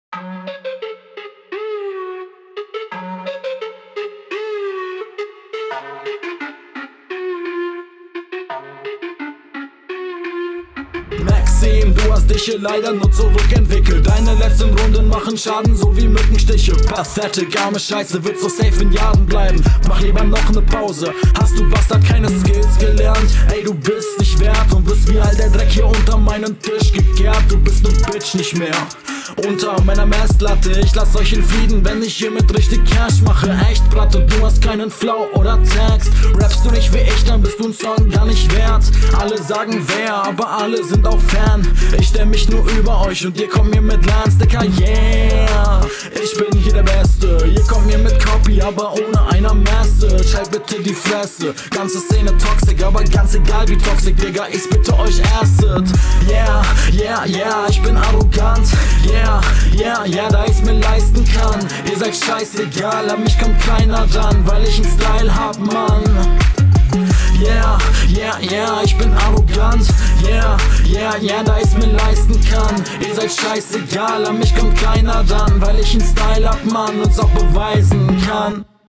Cool gerappt.
Ballert hart.